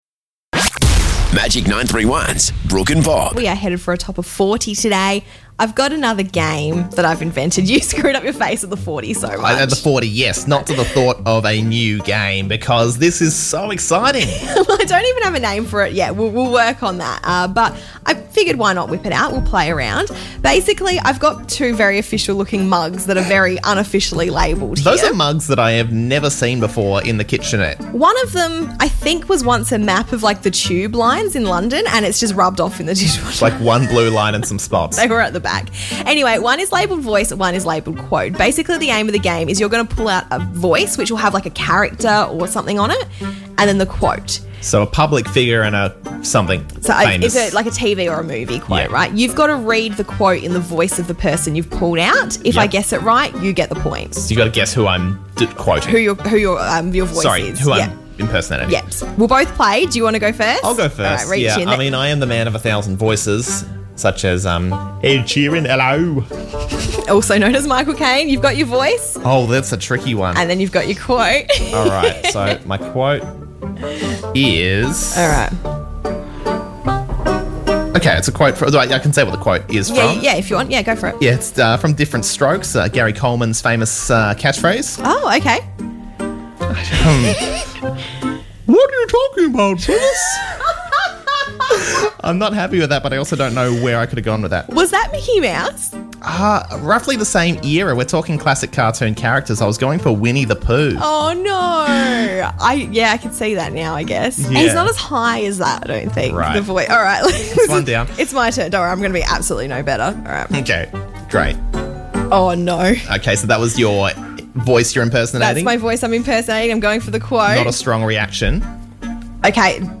Untitled Celebrity Impersonation Game